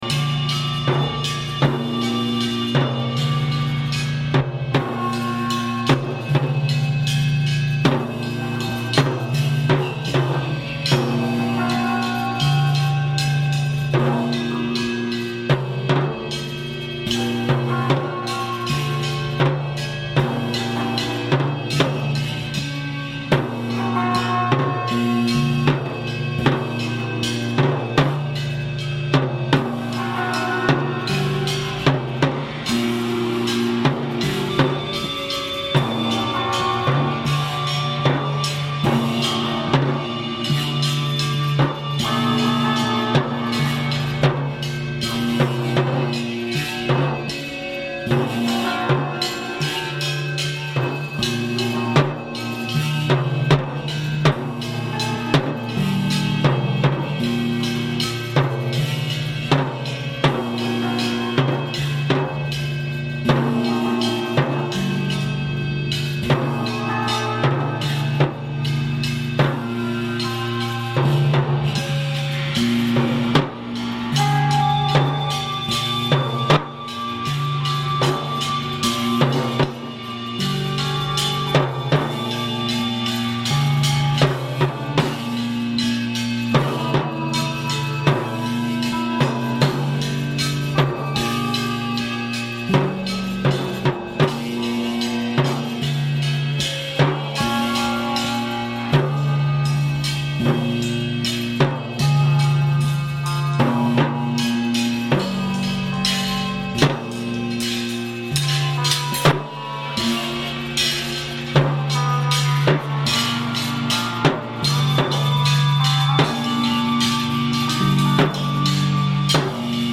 voice and percussion
noise, bass, and keyboards
guitars